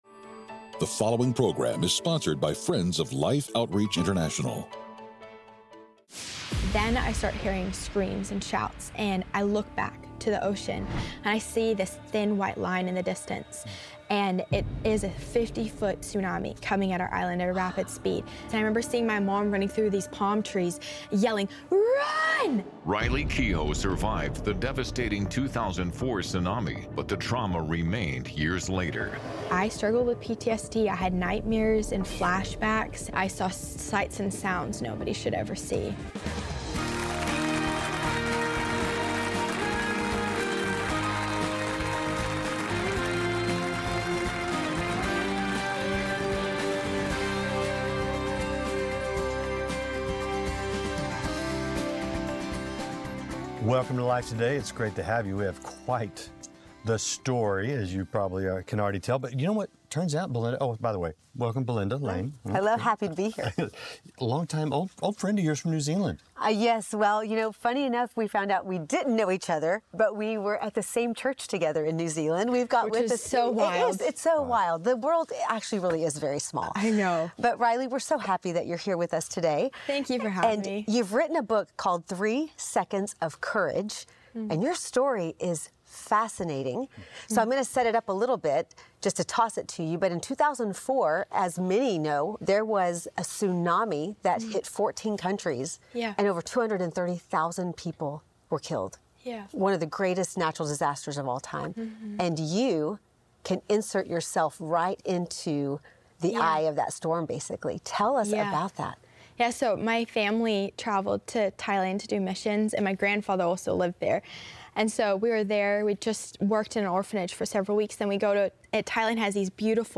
A woman who barely survived the massive 2004 tsunami shares her experience that day and her long journey to overcome the lingering fear.